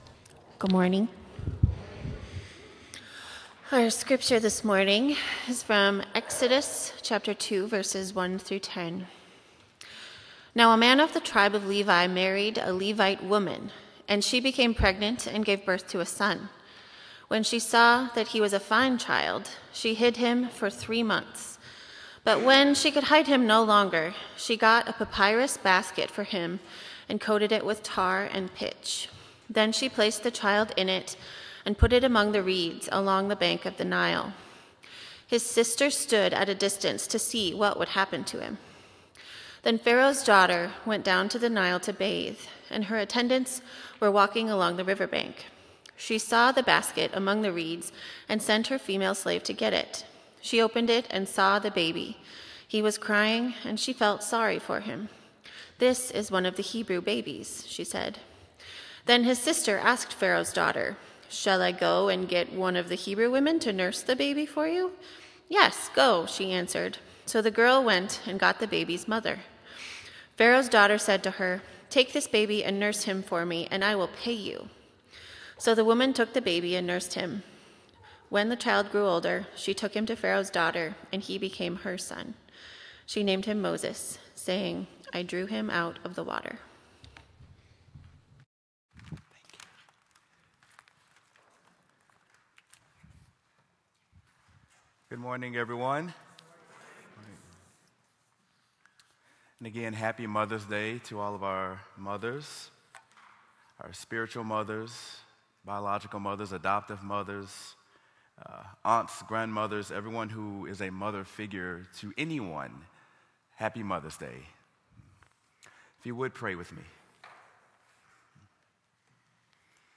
Sermon: Jochebed: A Mother Who Trusted God | Antioch Community Church - Minneapolis
sermon-jochebed-a-mother-who-trusted-god.m4a